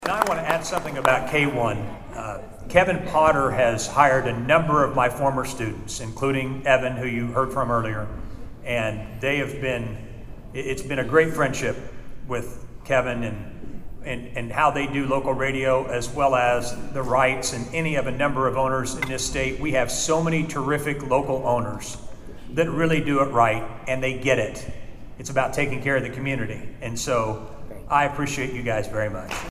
It was a huge night Thursday for your Bartlesville Radio stations at the Oklahoma Association of Broadcasters Awards Ceremony in Tulsa.